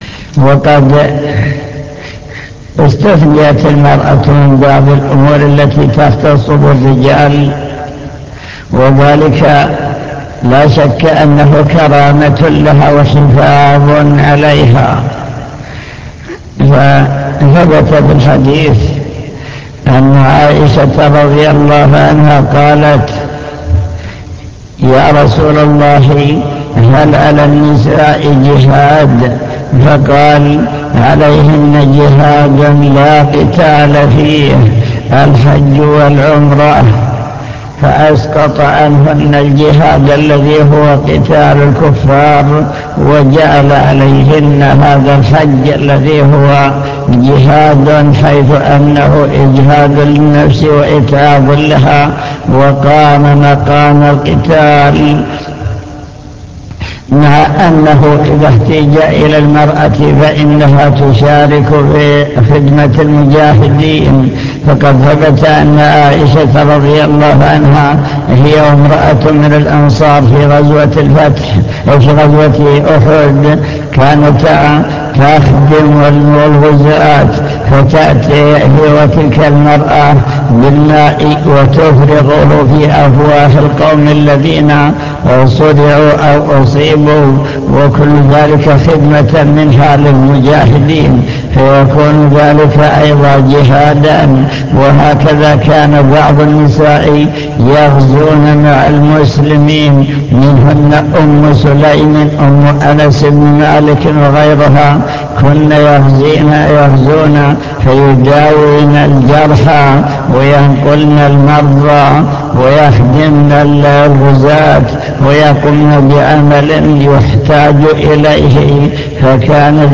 المكتبة الصوتية  تسجيلات - محاضرات ودروس  فتاوى عن المرأة